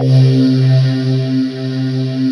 Index of /90_sSampleCDs/USB Soundscan vol.28 - Choir Acoustic & Synth [AKAI] 1CD/Partition D/17-GYRVOC 3D